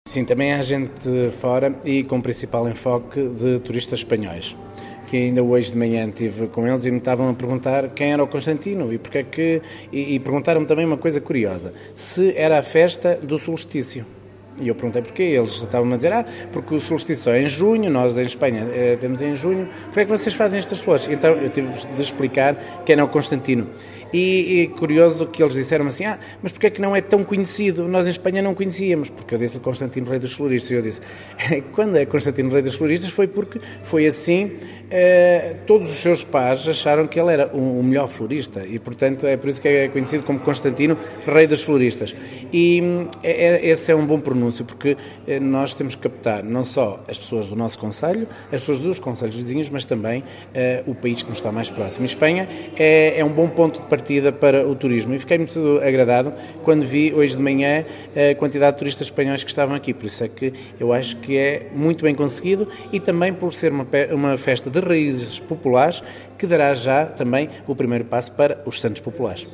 O presidente do município, Nuno Gonçalves, conta que a festa começa a atrair já os vizinhos espanhóis, e isso são bons prenúncios.